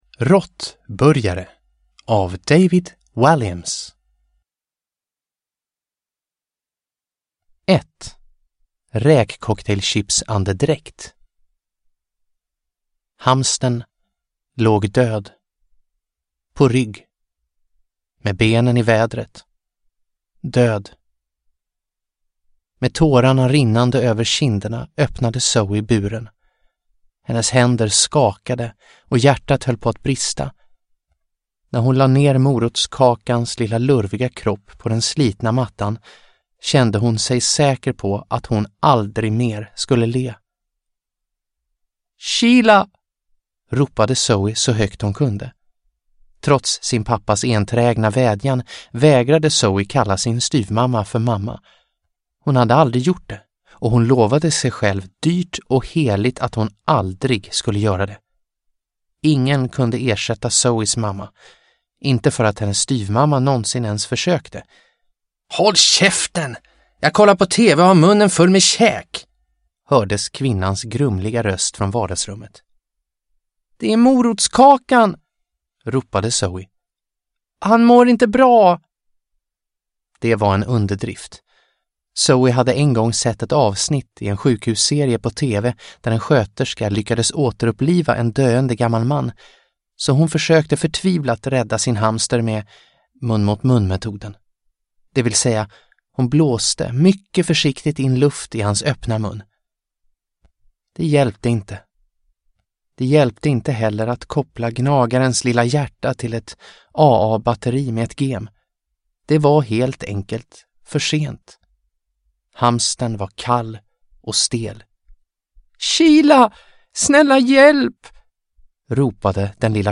Råttburgare – Ljudbok – Laddas ner